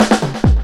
02_11_drumbreak.wav